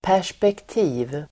Uttal: [pär_spekt'i:v]